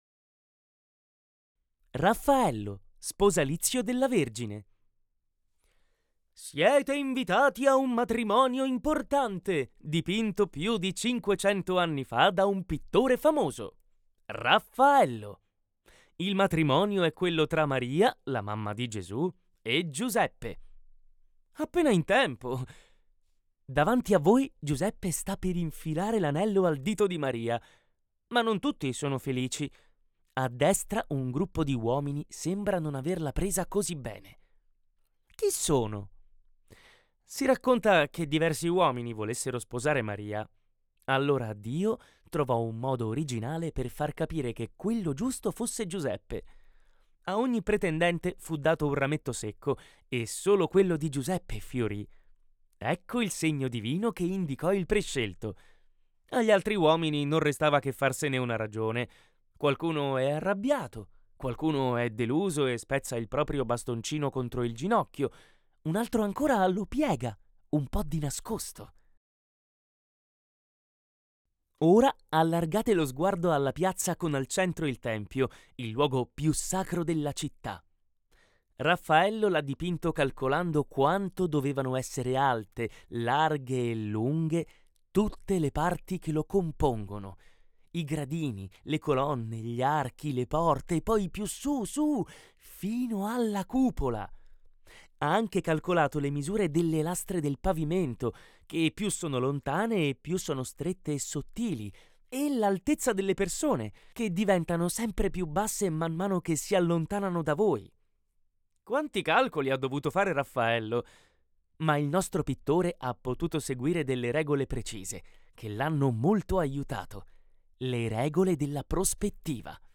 Human Audio